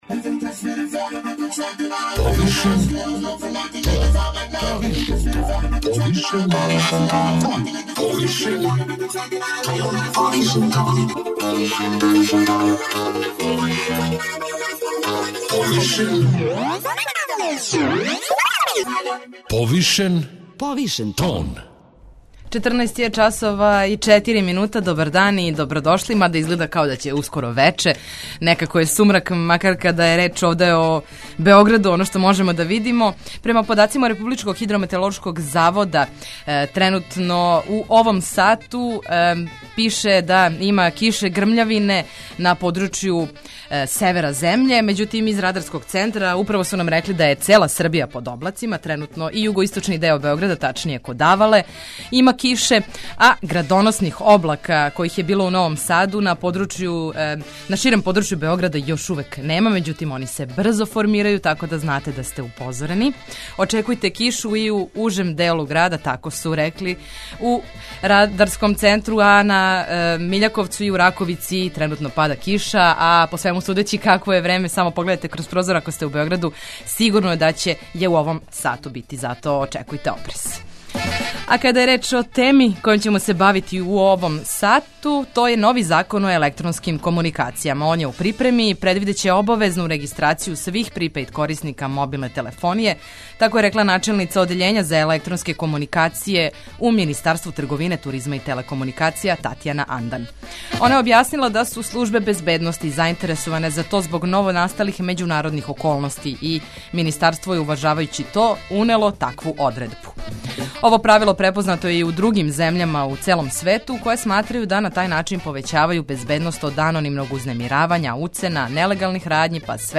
Повереник за заштиту података о личности, Родољуб Шабић , говори о оним деловима Закона који морају бити усаглашени у смислу приступа подацима и добрим и лошим странама овако замишљене регистрације.